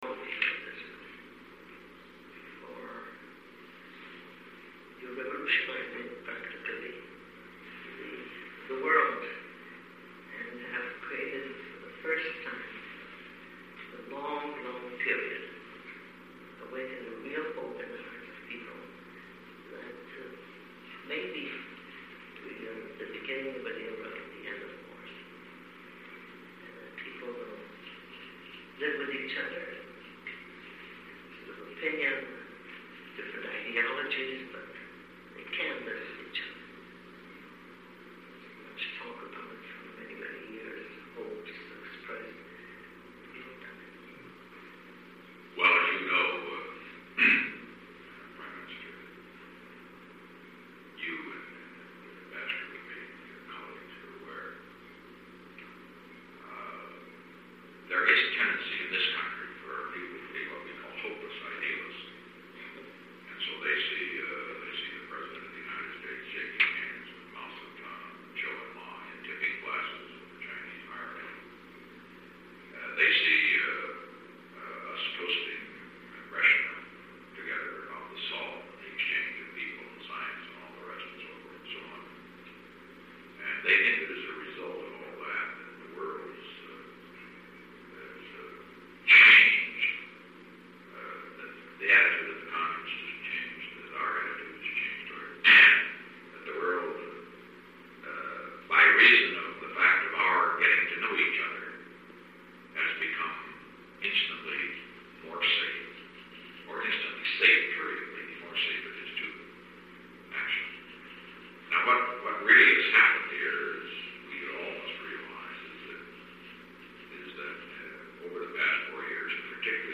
The transcribed clip below comes from a 1 March 1973 meeting between Nixon, Henry Kissinger, Golda Meir, Yitzhak Rabin, and Simcha Dinitz about Middle Eastern affairs. In this section, the President functions as an amateur diplomatic historian, offering offers his perspective on the tension between realism and idealism in US foreign policy, and how that pattern applied to Woodrow Wilson and the Versailles Treaty.
The overall quality of the recording (and even sections of the embedded clip below) sometimes isn’t that great.
nixon-meir-meeting.mp3